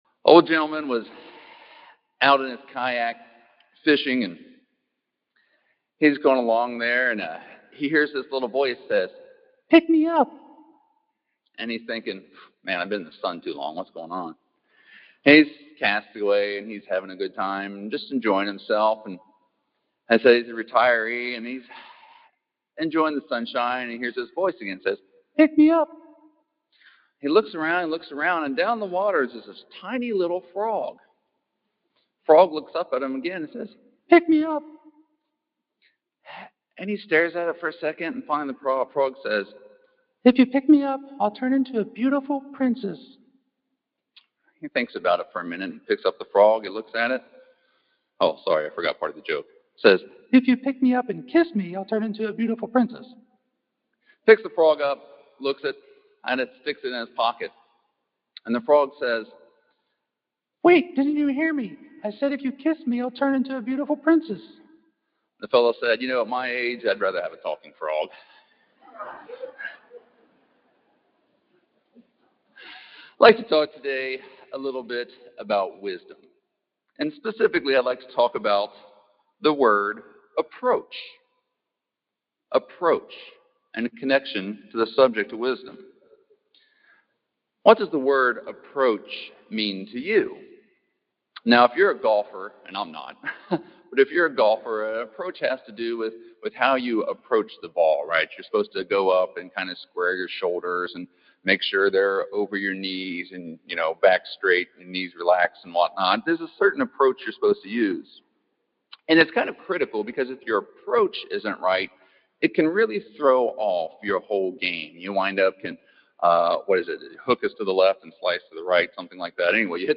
This sermon was given at the Panama City Beach, Florida 2022 Feast site.